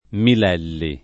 [ mil $ lli ]